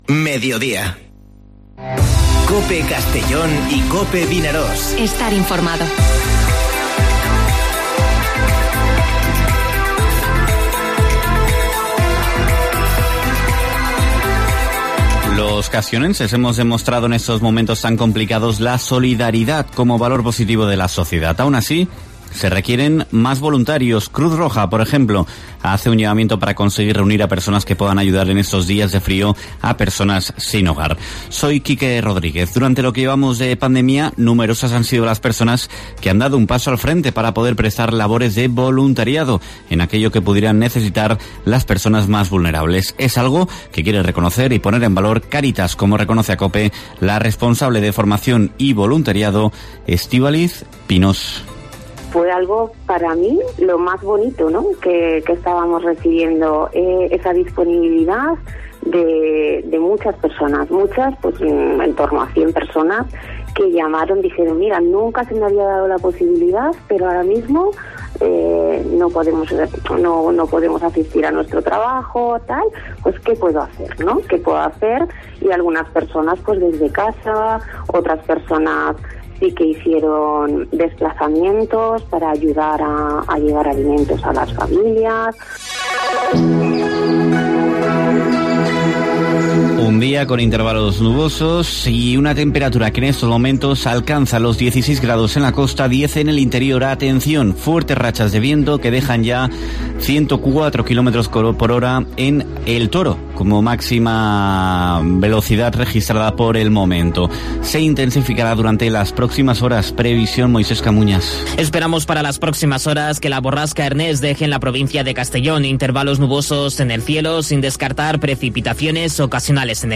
Informativo Mediodía COPE en la provincia de Castellón (07/12/2020)